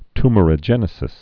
(tmər-ə-jĕnĭ-sĭs, ty-)